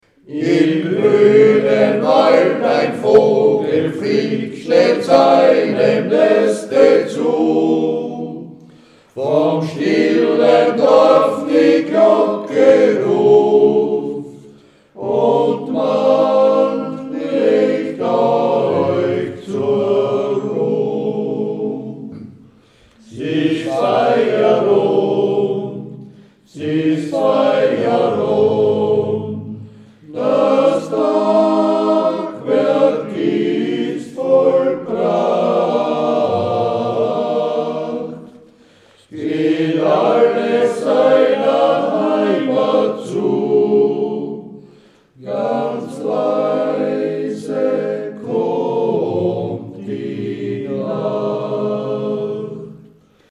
(Texte und Gesangsproben).
Feierabend, op. 30 von Anton Gürtler, 1909 (Clementisänger Gusswerk)